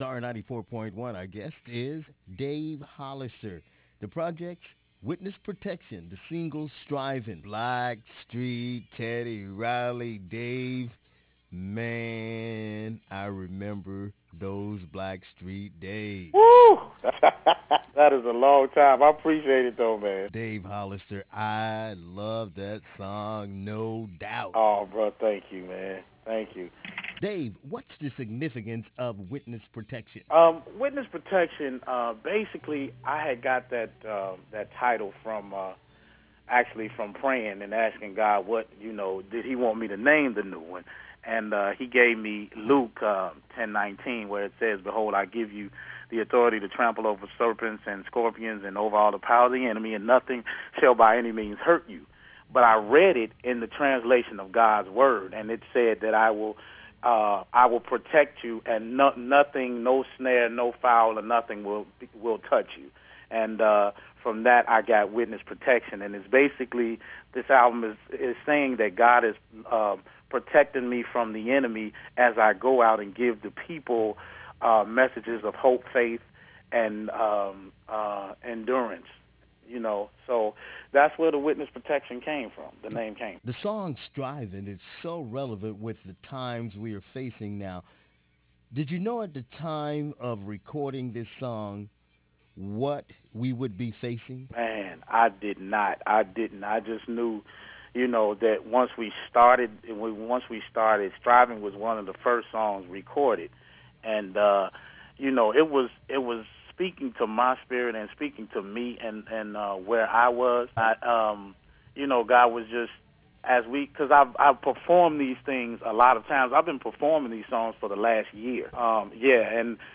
Dave Hollister- Interview